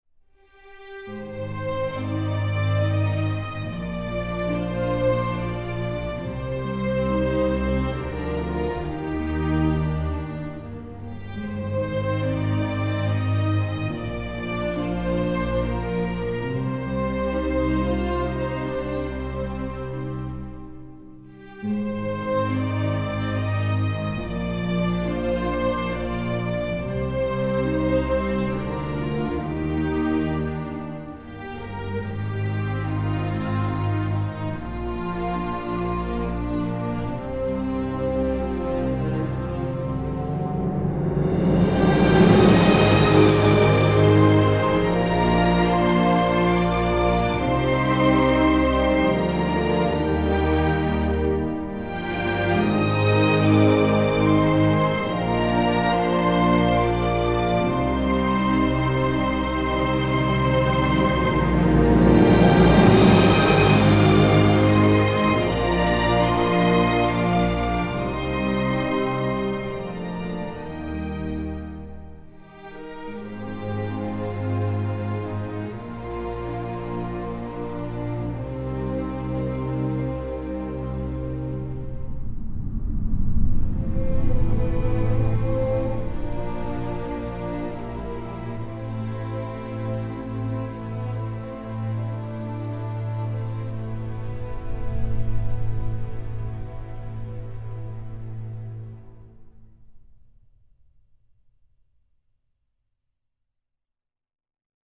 女声